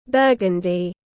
Màu đỏ rượu vang tiếng anh gọi là burgundy, phiên âm tiếng anh đọc là /ˈbɜː.ɡən.di/.
Burgundy /ˈbɜː.ɡən.di/
Burgundy.mp3